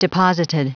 Prononciation du mot deposited en anglais (fichier audio)
deposited.wav